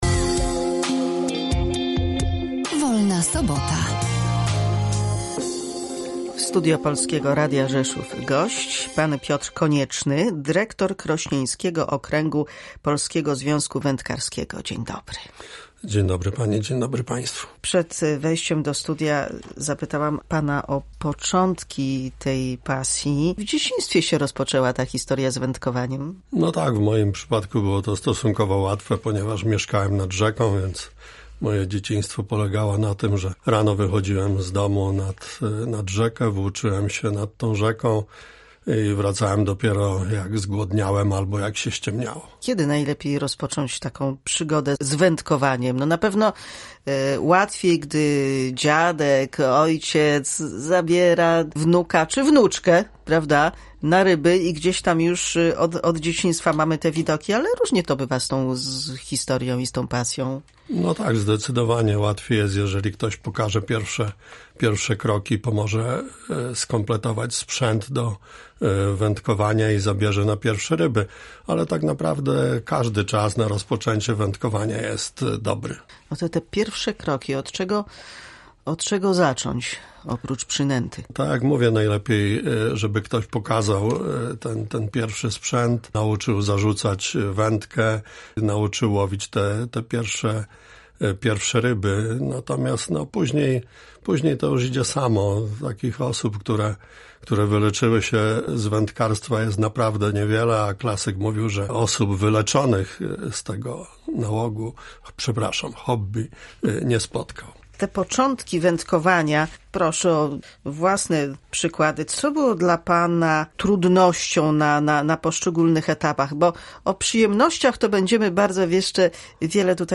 Dizen-ryby-rozmowa.mp3